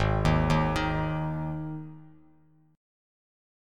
Ab5 Chord
Listen to Ab5 strummed